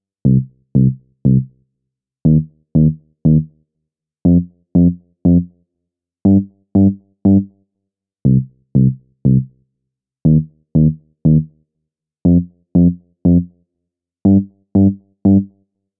synctest 1-Boffner Bass.wav